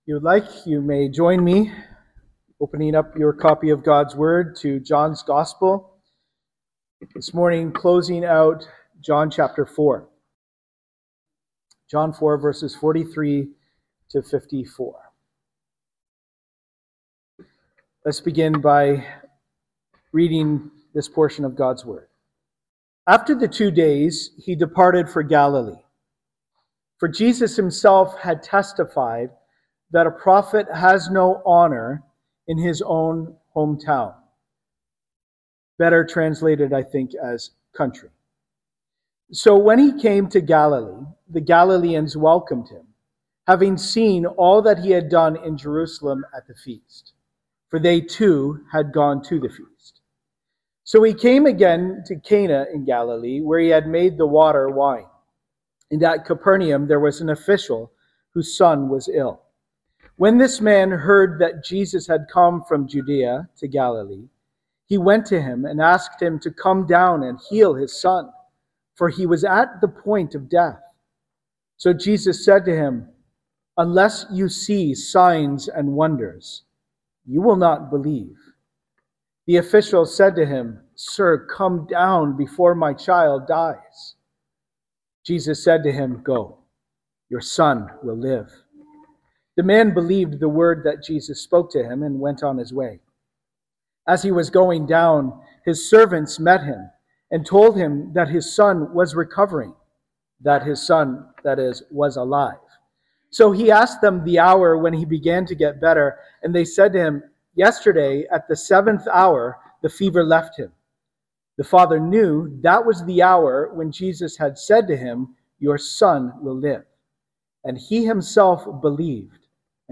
Sermons - Cannington Baptist Church